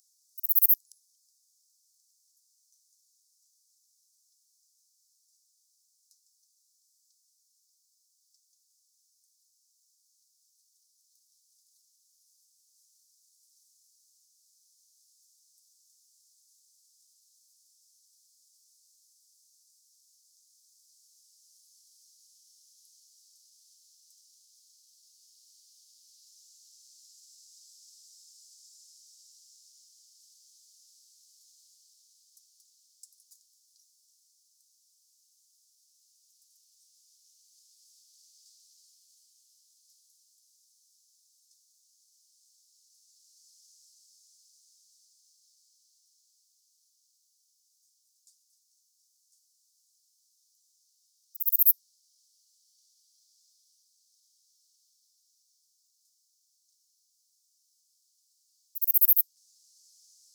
fork-tailed bush katydid
60 s of calling song and waveform; Los Angeles County, California; 16.7 °C.